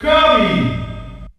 The announcer saying Kirby's name in German releases of Super Smash Bros.
Kirby_German_Announcer_SSB.wav